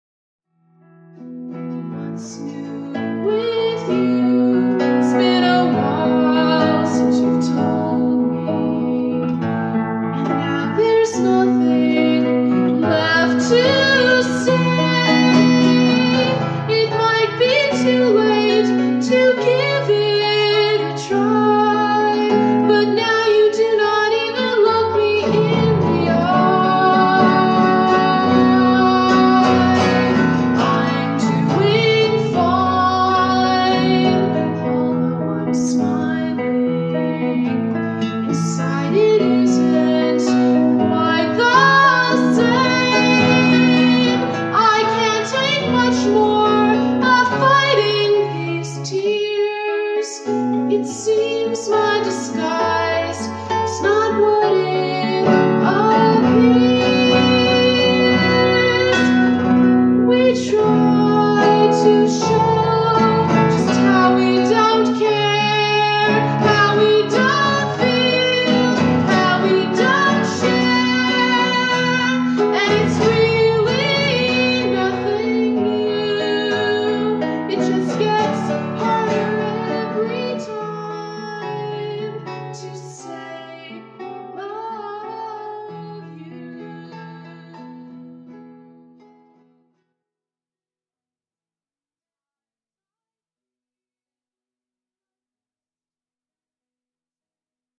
The verse and chorus had two distinctly different rhythms.
how-we-dont-care-1980-cassette.mp3